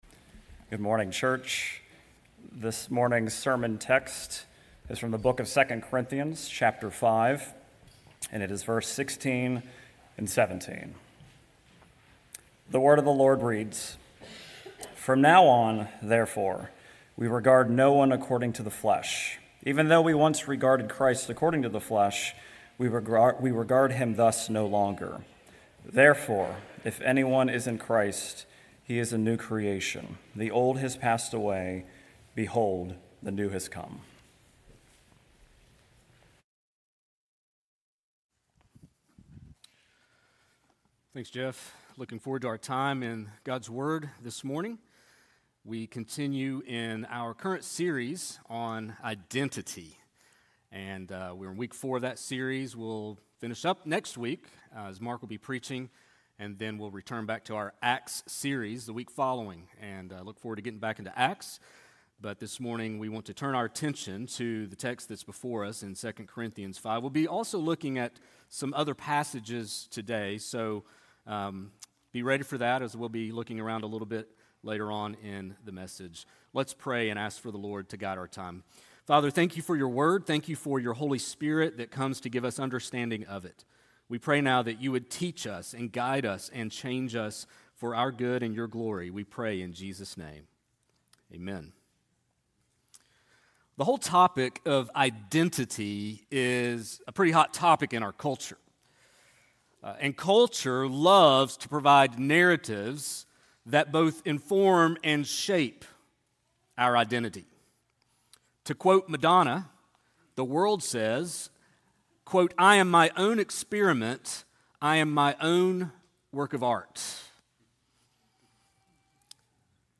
sermon8.24.25.mp3